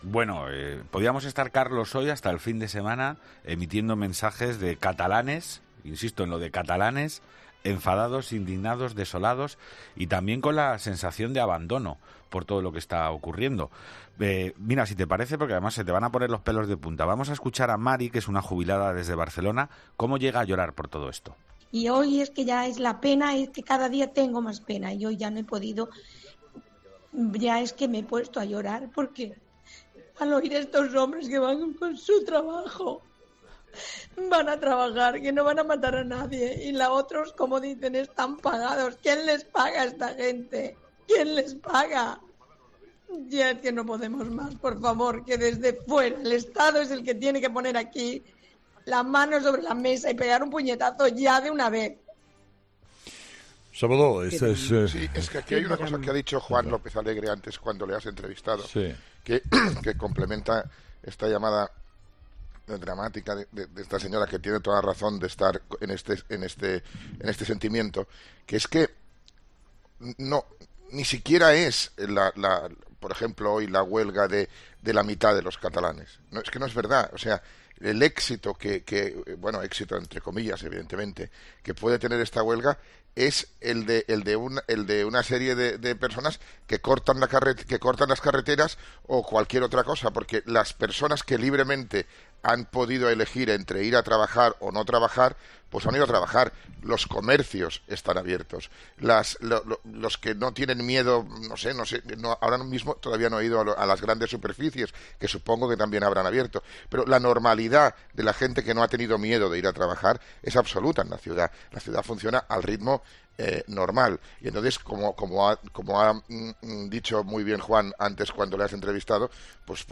Las lágrimas de una catalana desolada por la presión independentista
Una jubilada de Barcelona llama a 'Herrera en COPE' para pedir amparo ante la barbarie de los secesionistas